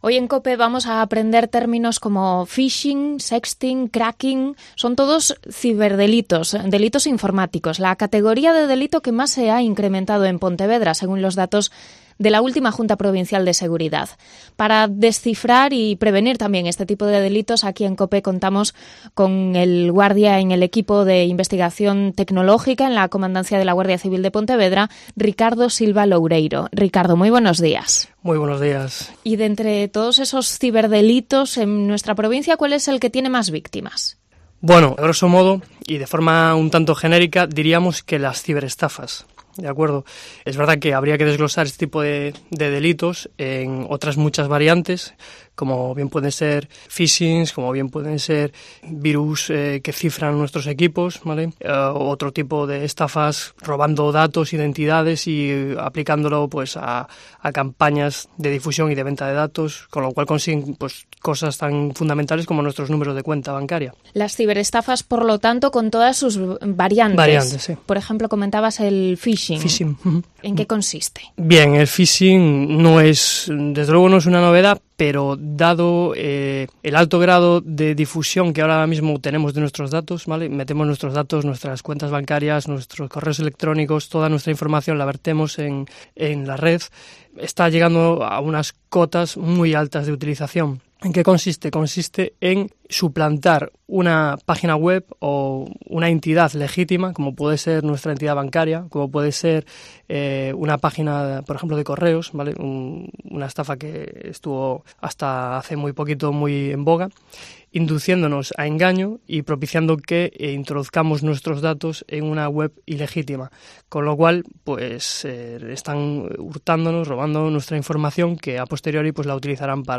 Entrevista a uno de los guardias del equipo de investigación tecnológica de Pontevedra